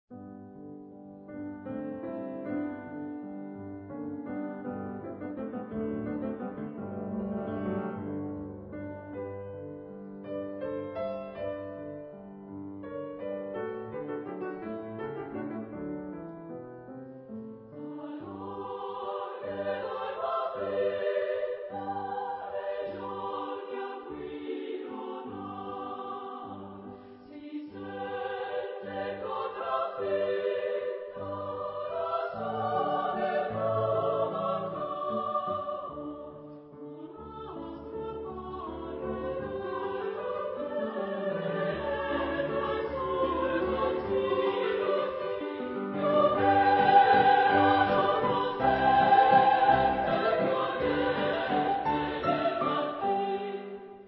Epoque: 19th century
Genre-Style-Form: Romantic ; Motet ; Sacred
Type of Choir: SSA  (3 women voices )
Instruments: Piano (1)
Tonality: G major